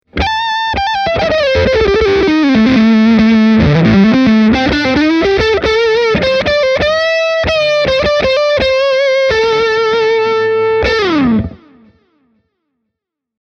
Putting good descriptions to sounds is always rather hard – so take a listen to the soundbites I recorded for you, using my Fender ’62 Telecaster Custom -reissue, as well as my Hamer USA Studio Custom. All delays and reverbs have been added at during mixdown.
Telecaster – overdriven lead
telecaster-e28093-solo-distortion.mp3